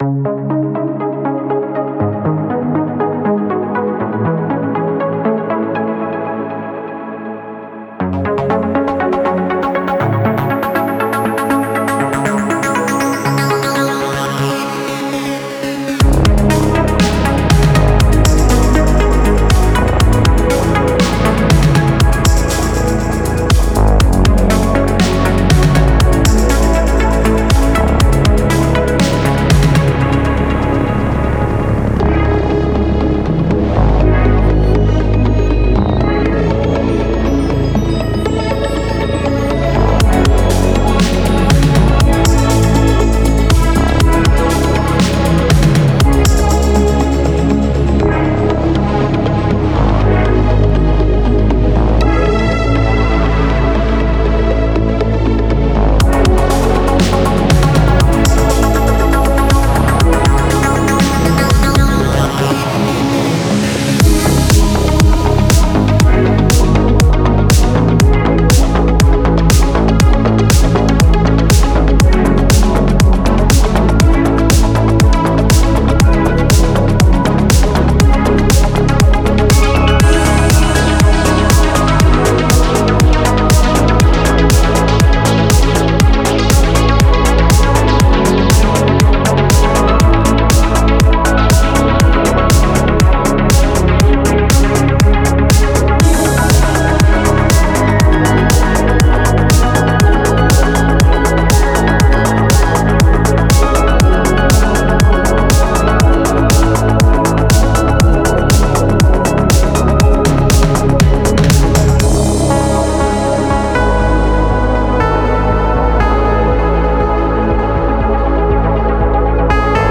Kolmen kaveruksen kimppabiisi kompoille. Parin päivän toteutusaika moderneilla daweilla (Reaper, Bitwig & FLStudio).